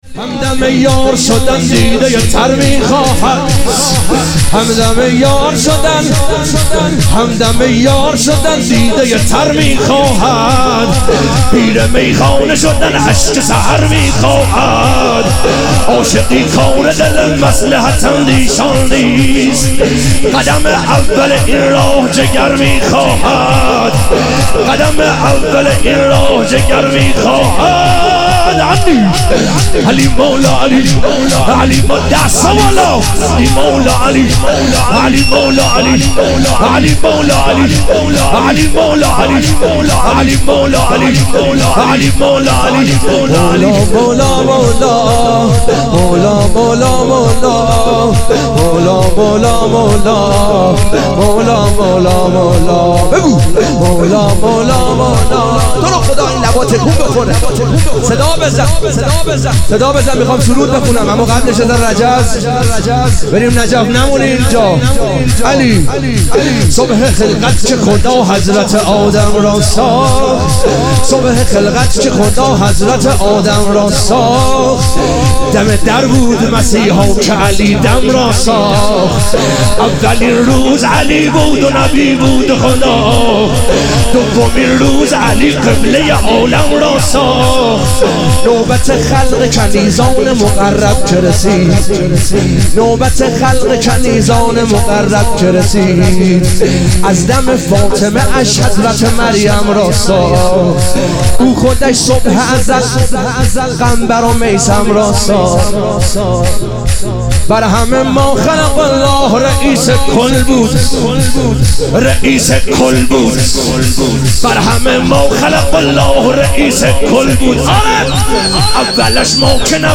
ظهور وجود مقدس امام رضا علیه السلام - شور